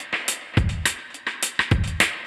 Index of /musicradar/dub-designer-samples/105bpm/Beats
DD_BeatB_105-01.wav